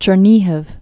(chər-nēhəv, -nyēhēw) or Cher·ni·gov (-gəf)